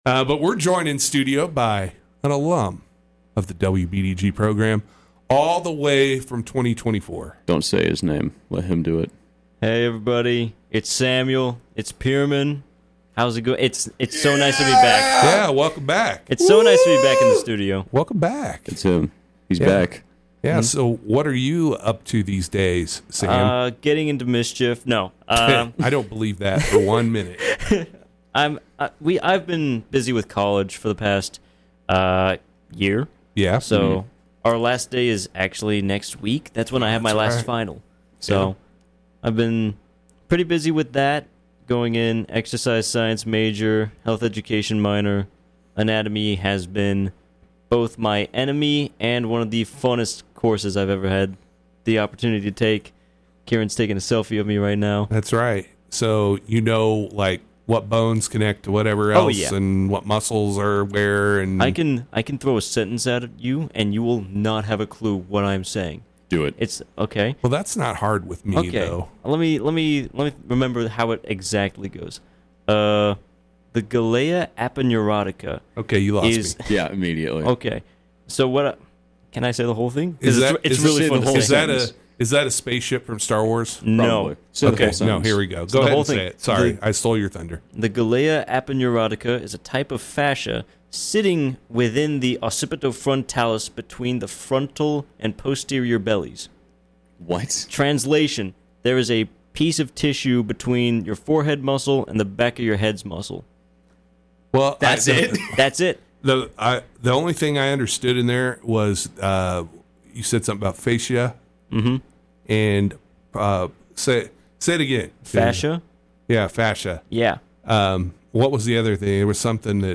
WBDG 59 Marathon Interview